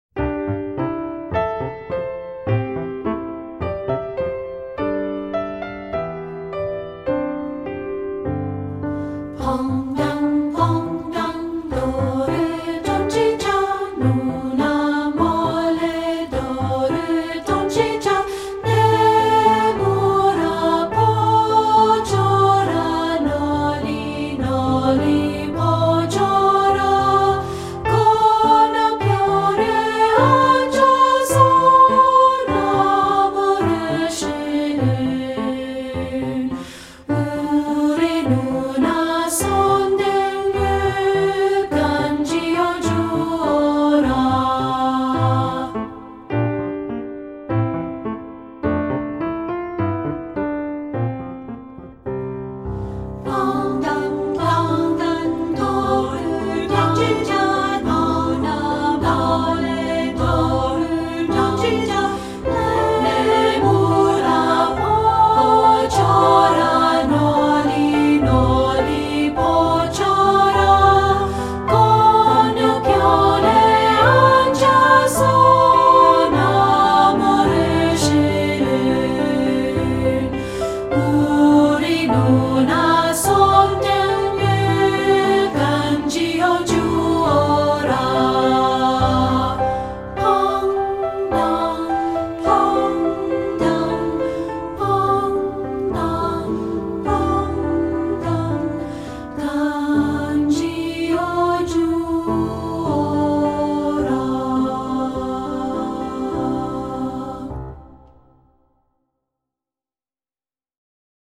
Composer: Korean Folk Song
Voicing: 2-Part